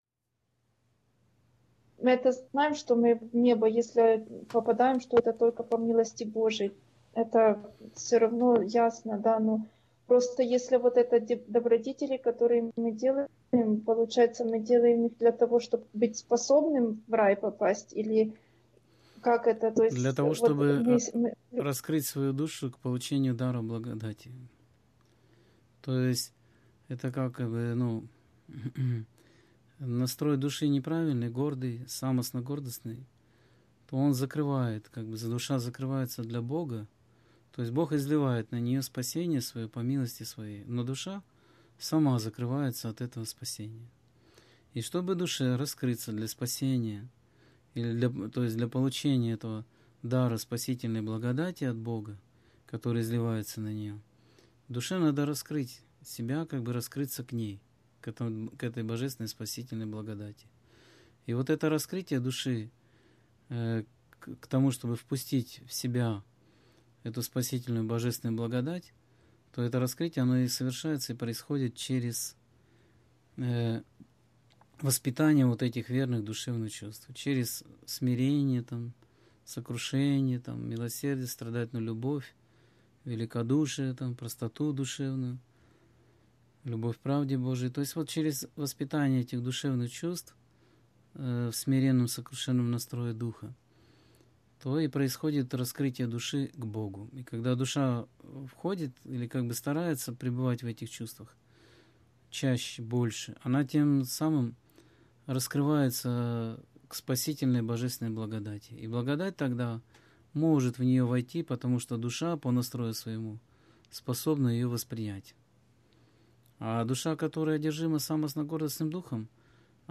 Скайп-беседа 19.03.2016 — ХРИСТИАНСКАЯ ЦЕРКОВЬ